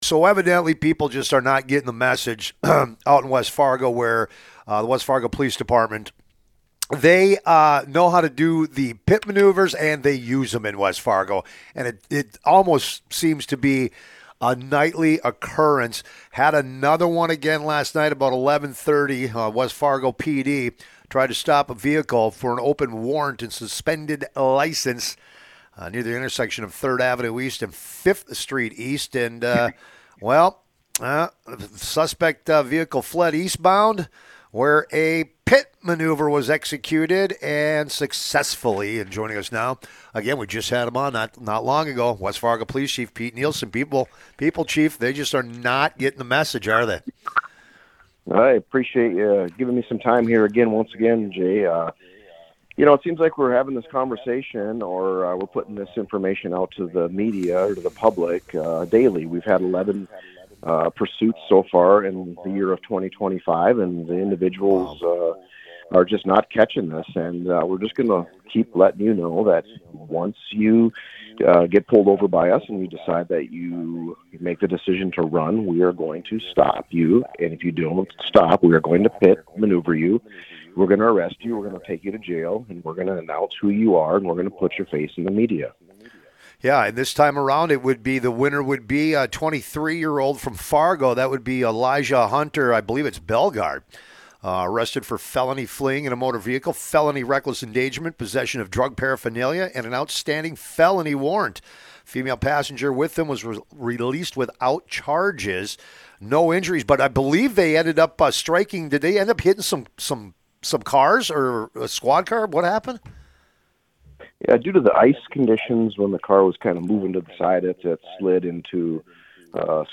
West Fargo Police Chief Pete Nielsen interviewed